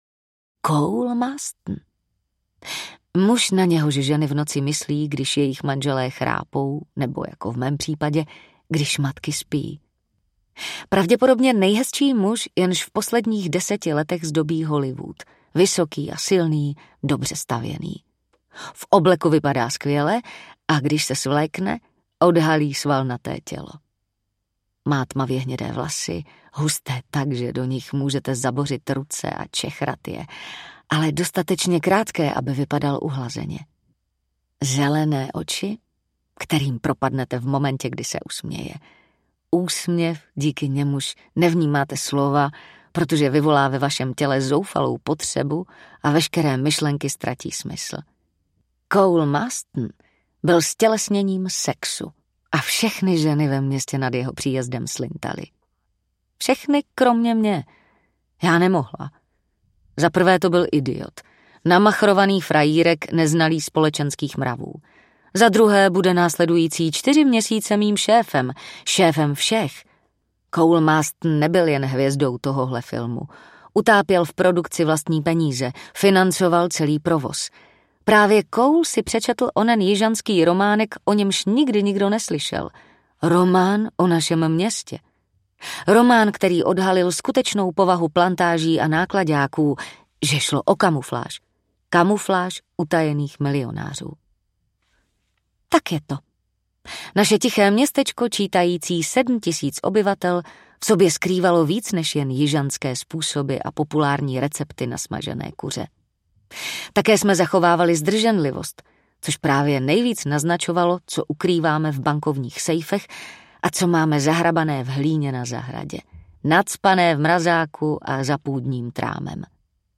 Hříchy Hollywoodu audiokniha
Ukázka z knihy